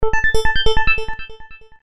Короткие рингтоны , Рингтоны на смс и уведомления
звонкие , электронные